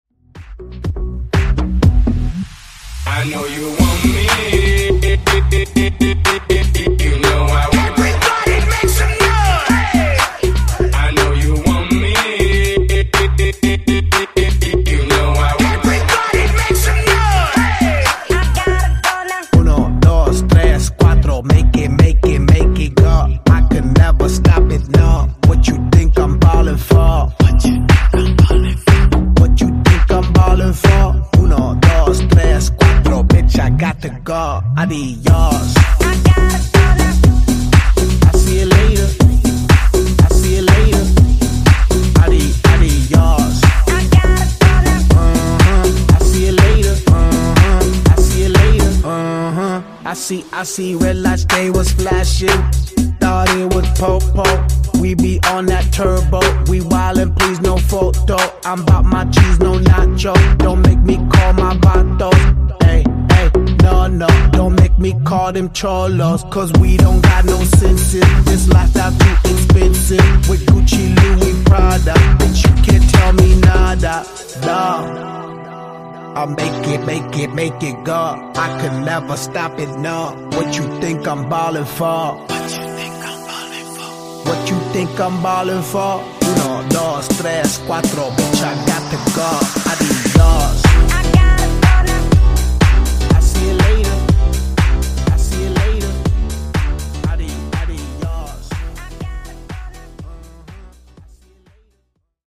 Genres: R & B , RE-DRUM
Clean BPM: 87 Time